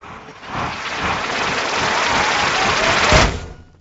Cog_Death.ogg